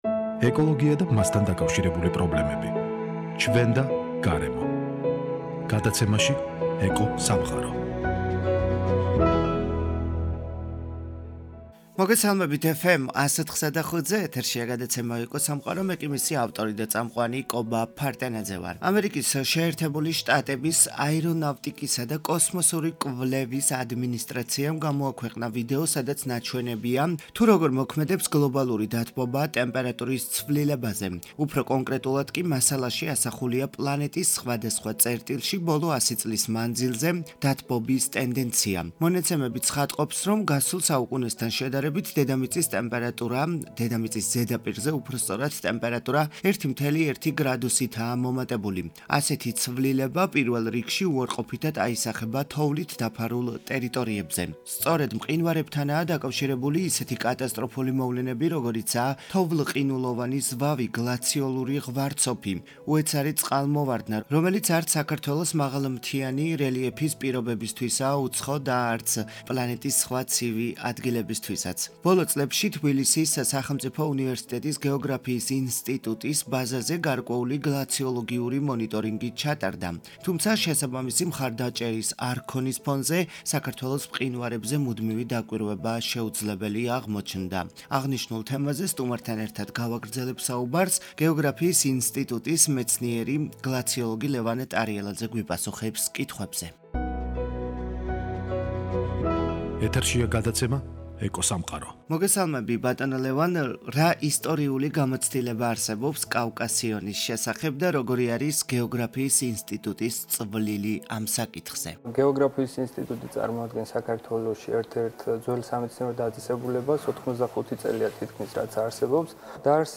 აღნიშნულ თემაზე სტუმართან ერთად ვსაუბრობ გადაცემაში